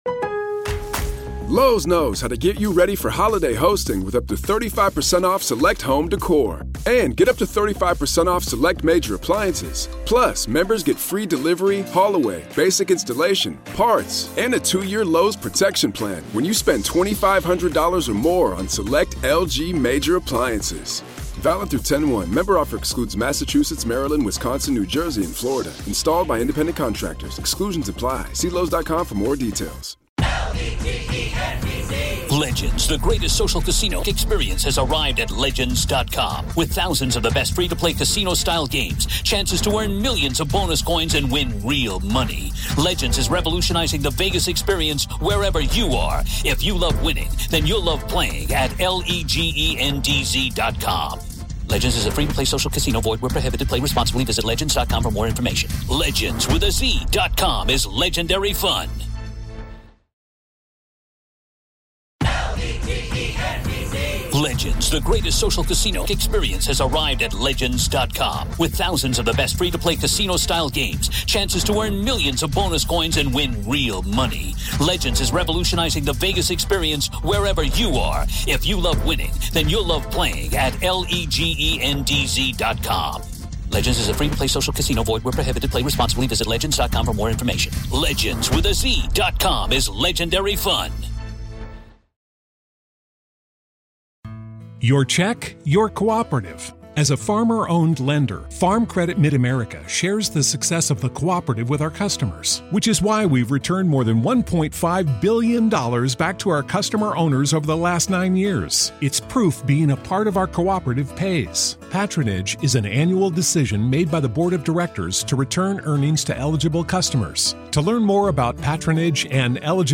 If you enjoy our interviews and conversations about "The Dead," why not listen ad-free?